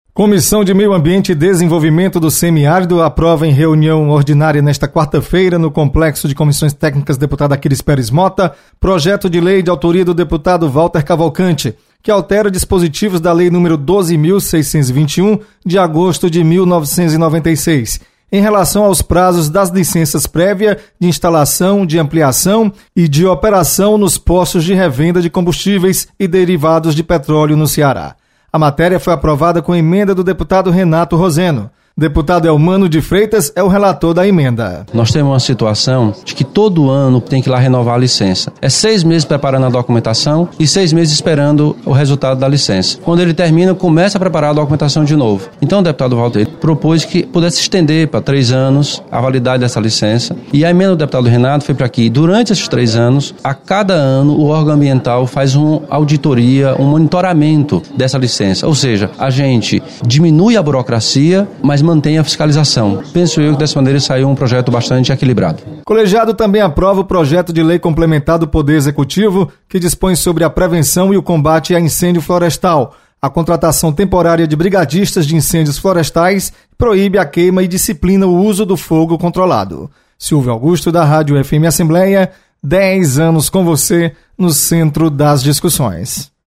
Comissão de Meio Ambiente e Desenvolvimento do Semiárido realiza reunião nesta quarta-feira. Repórter